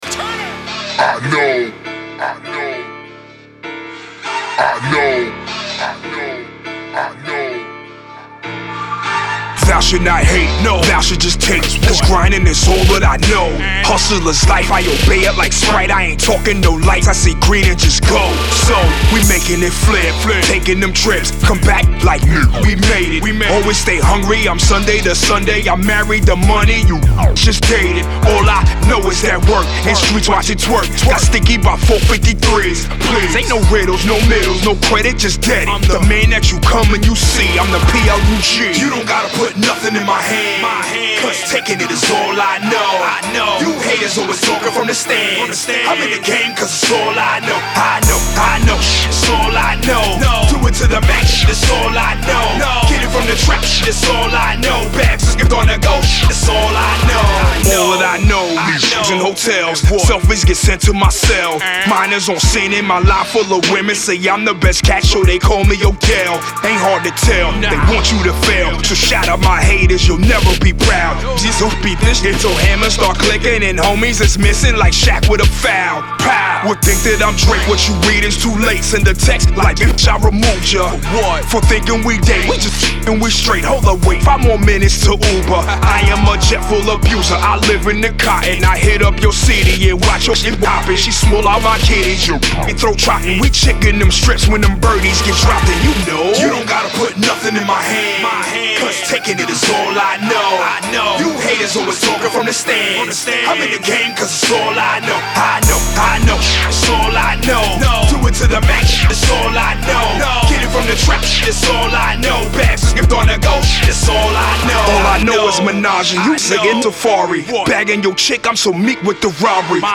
Strong, Precise and Hot As FCK!